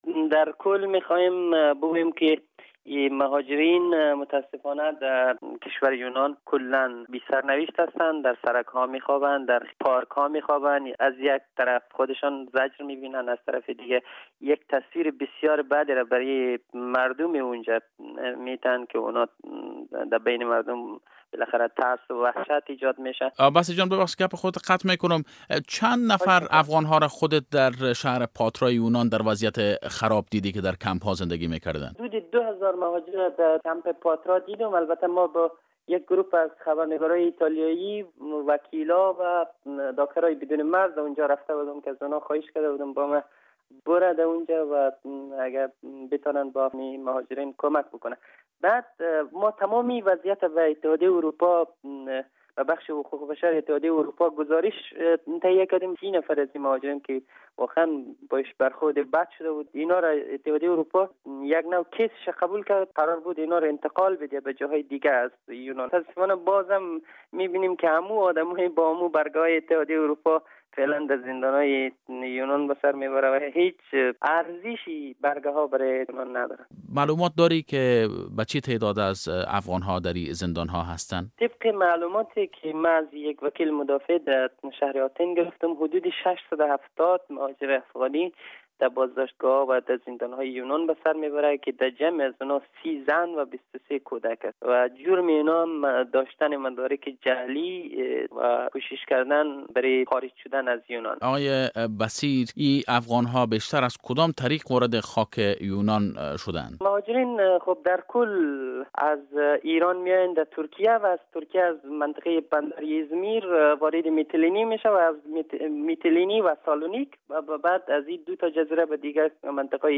مصاحبه با یک محصل افغان در ایتالیا